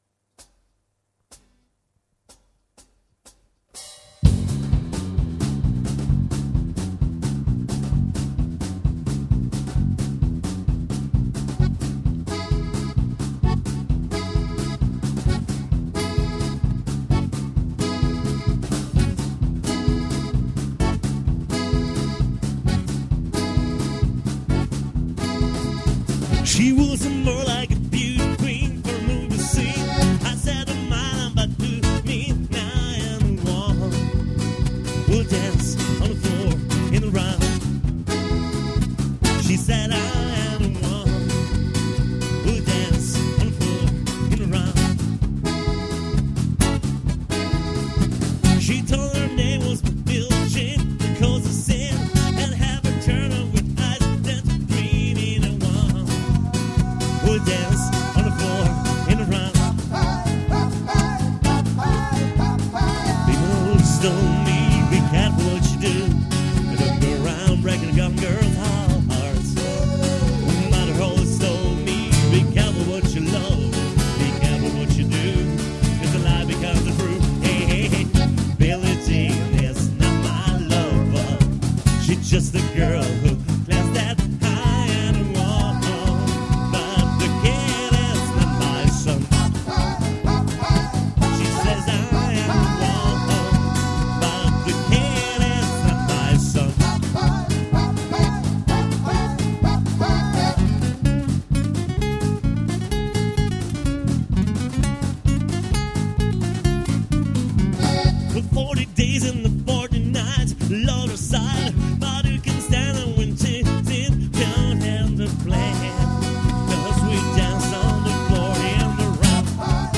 gipsy,folk,rock